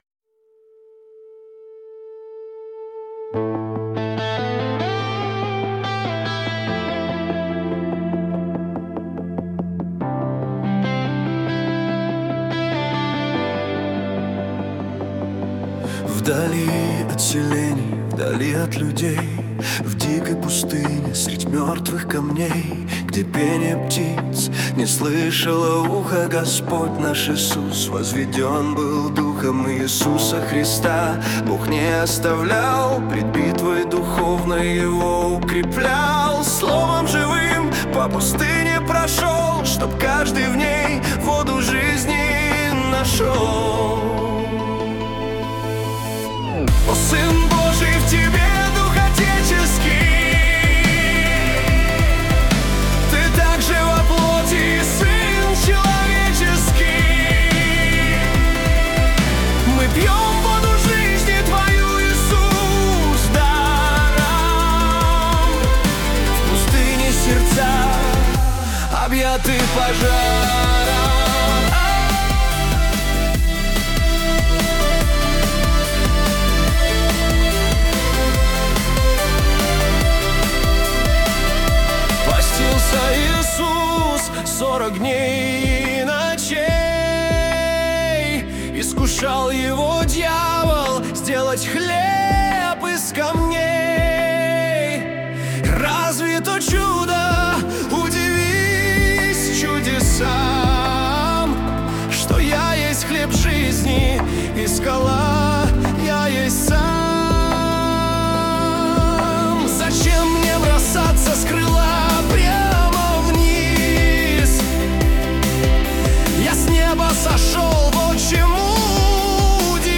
песня ai
116 просмотров 528 прослушиваний 45 скачиваний BPM: 72